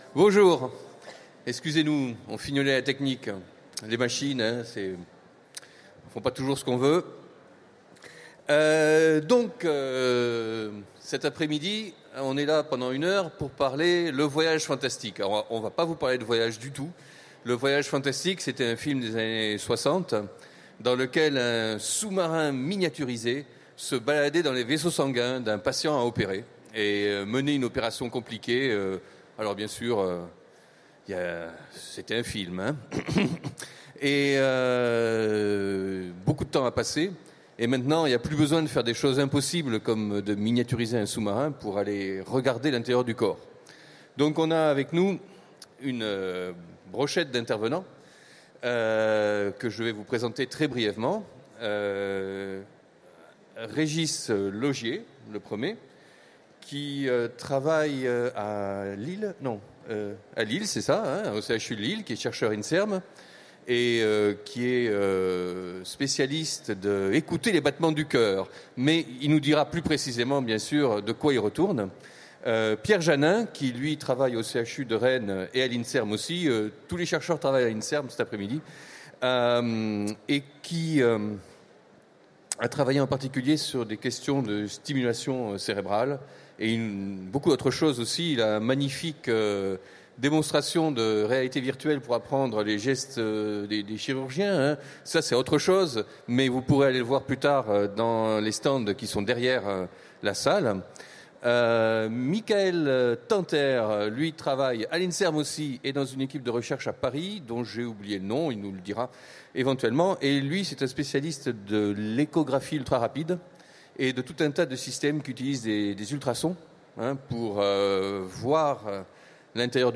Utopiales 2016 : Conférence Le voyage fantastique